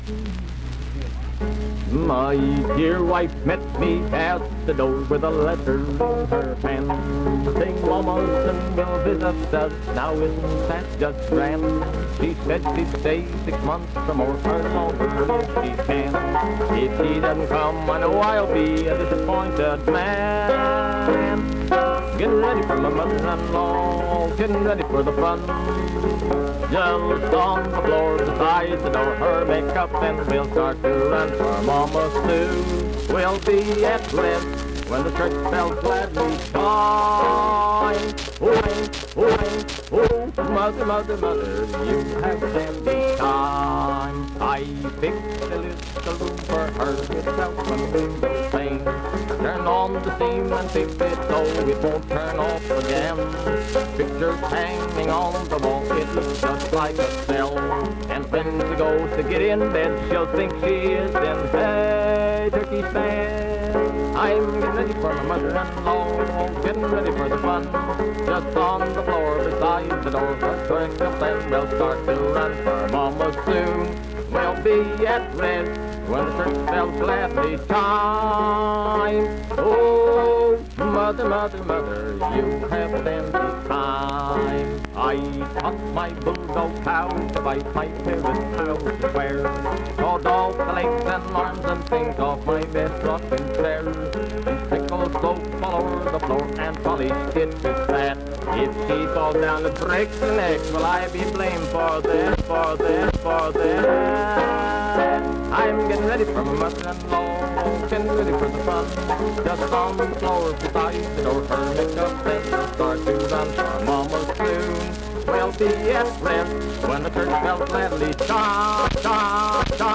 Vocal performance accompanied by banjo.
Voice (sung), Banjo
Vienna (W. Va.), Wood County (W. Va.)